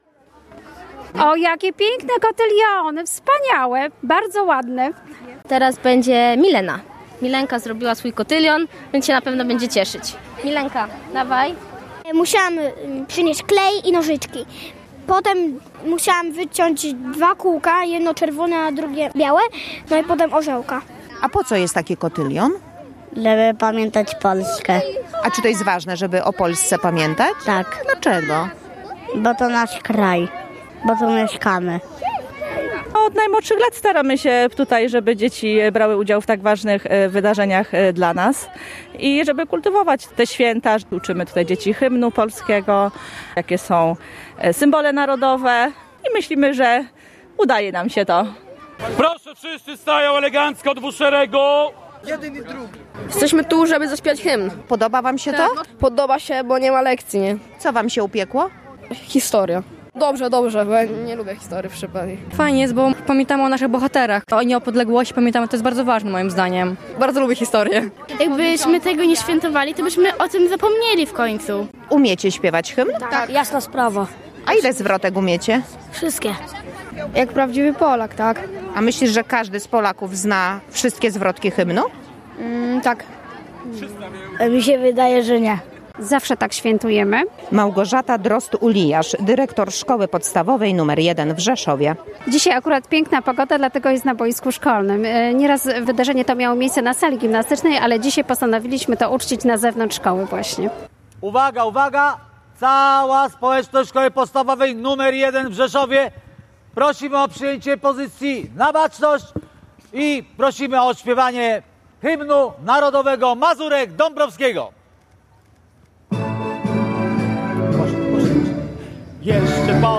Wspólne śpiewanie w Rzeszowie
Cała społeczność szkolna zgromadziła się na boisku, by wspólnie odśpiewać hymn Polski.